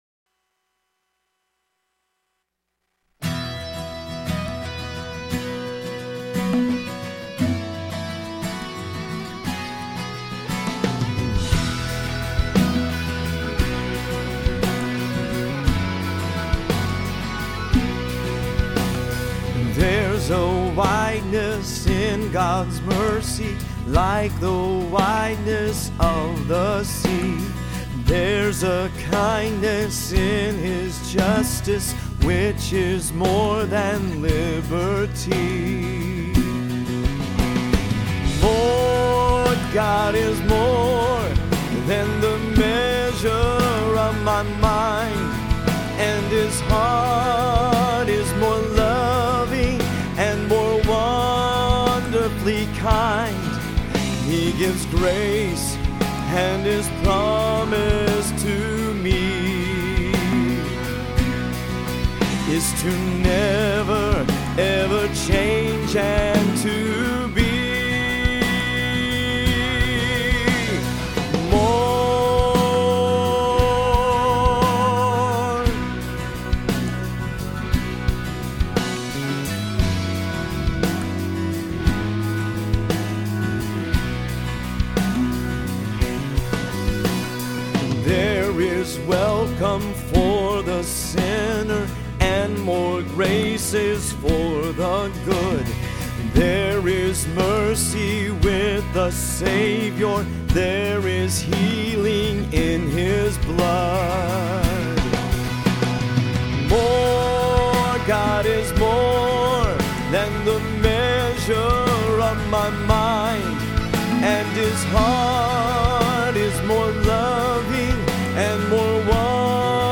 More-MS_vocal.mp3